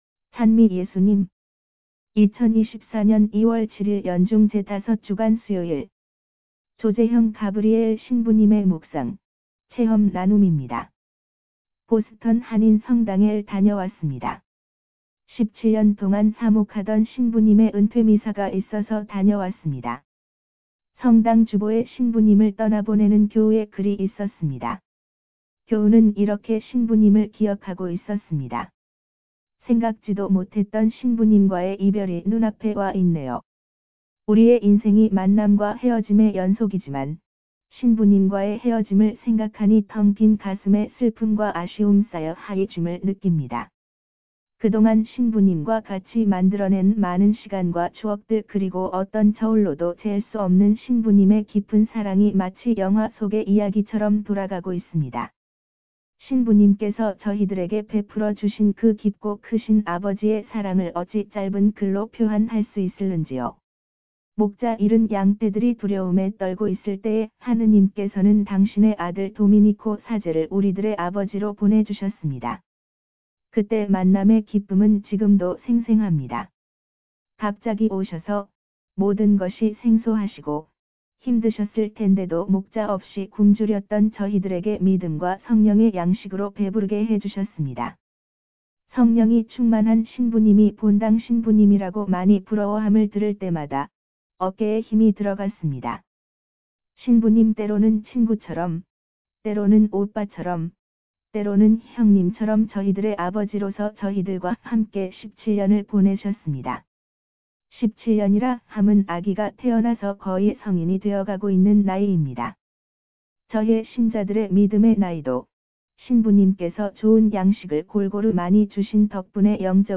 강론